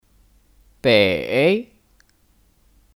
北 (Běi 北)